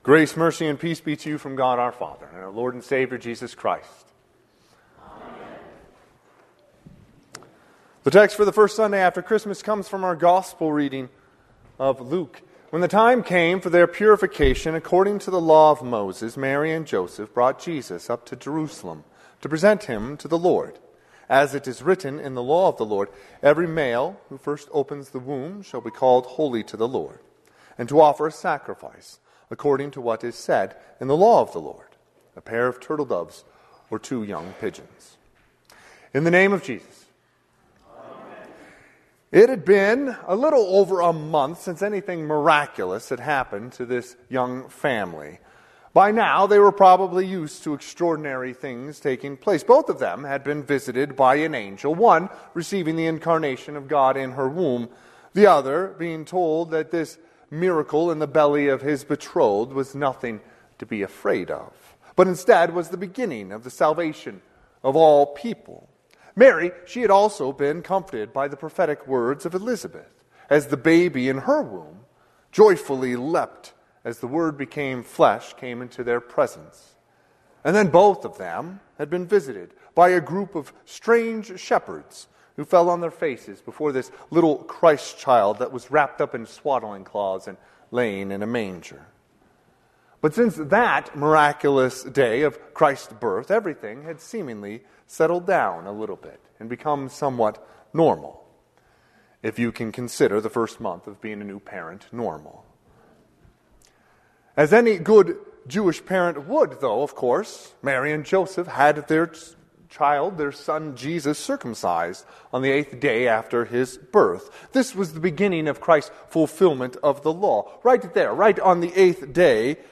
Sermon - 12/29/2024 - Wheat Ridge Lutheran Church, Wheat Ridge, Colorado
First Sunday after Christmas